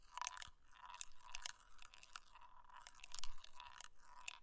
Descarga de Sonidos mp3 Gratis: canica 1 .
canica-1-.mp3